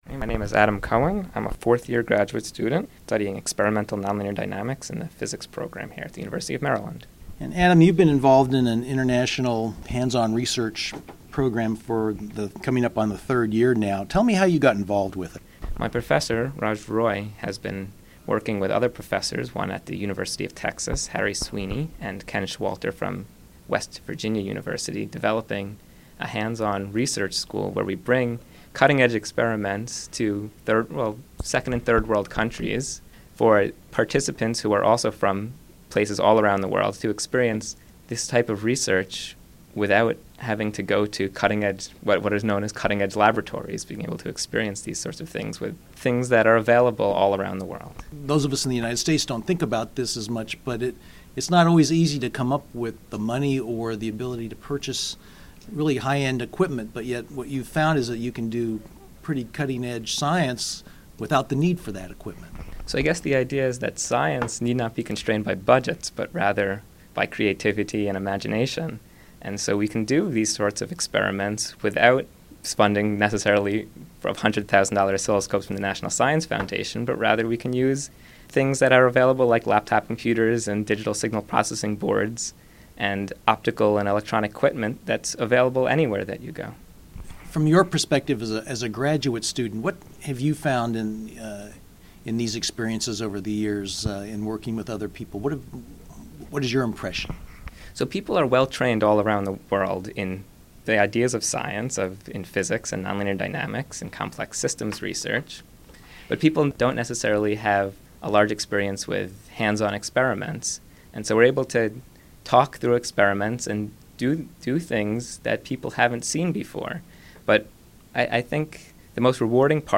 Hands on Science Interview